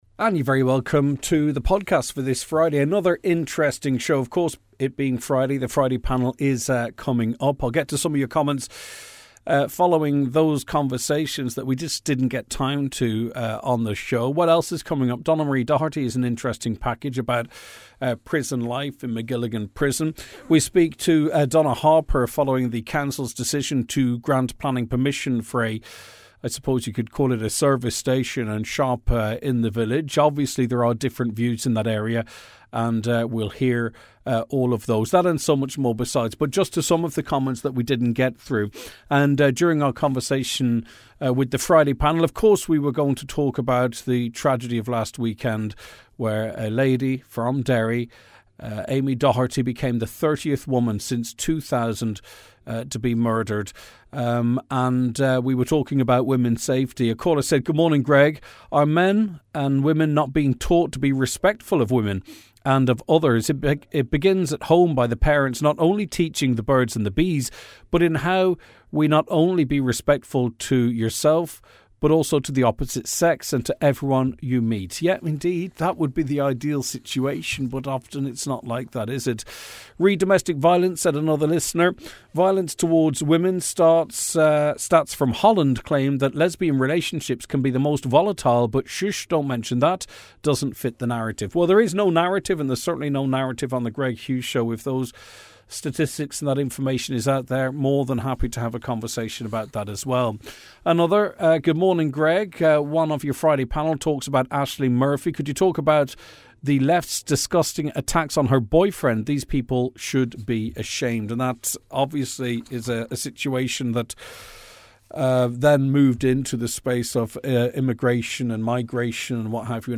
Today’s episode is a packed one, featuring a deep dive into the week’s biggest headlines with our Friday Panel and moving personal stories from across the Northwest.